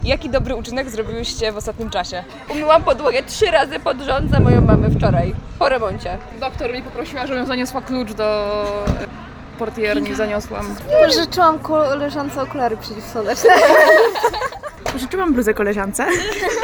Dzień Dobrych Uczynków – małe gesty, wielka zmiana (sonda studencka)